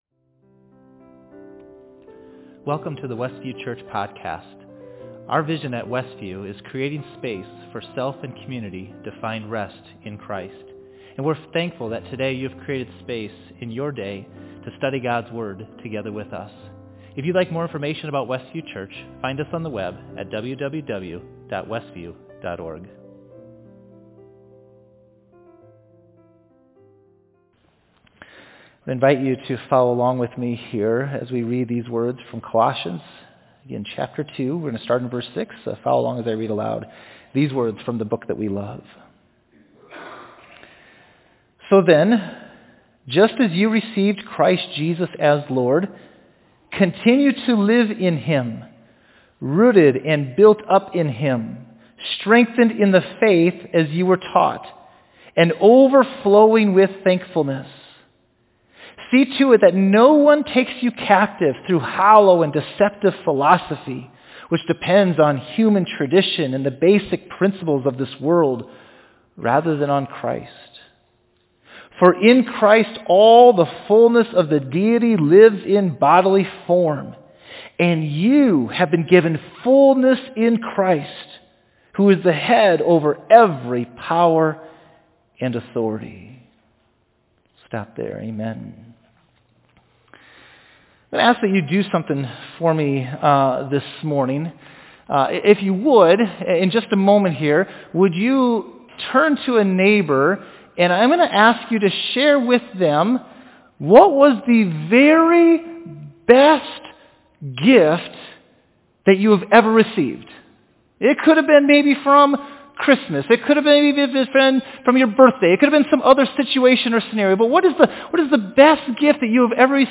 Facebook Livestream